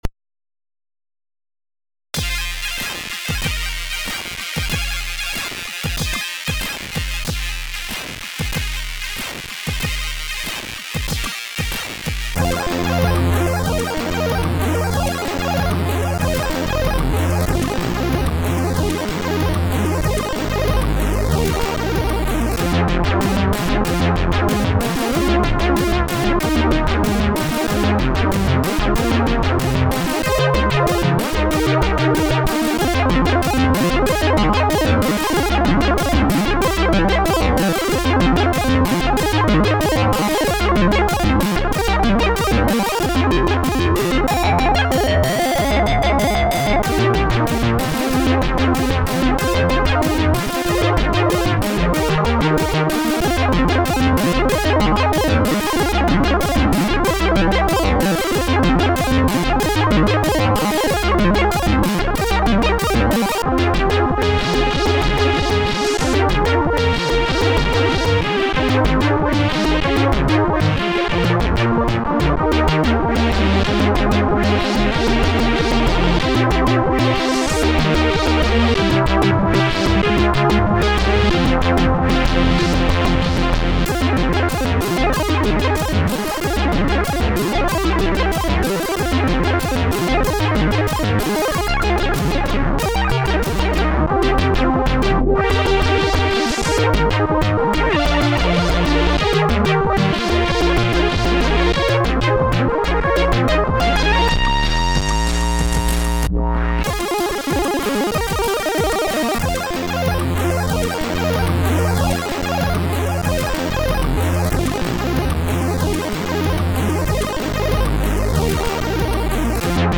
SID Version: 8580 (PAL)